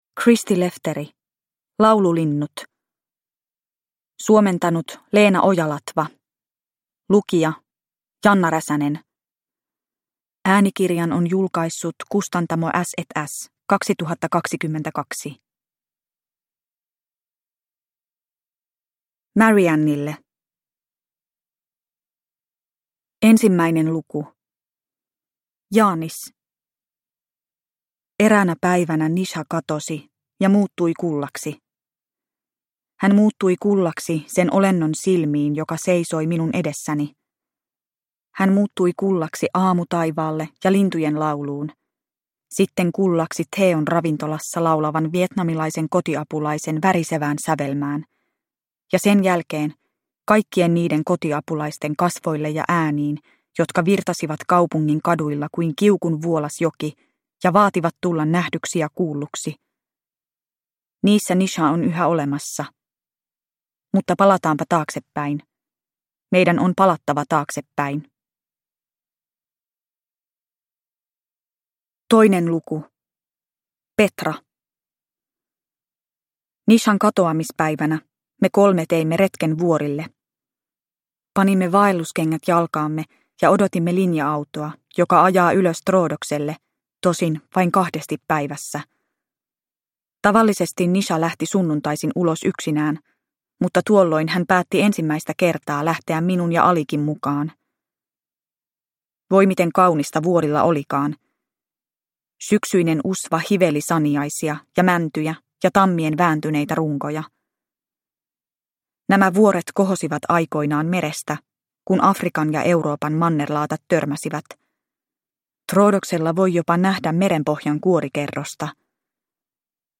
Laululinnut – Ljudbok – Laddas ner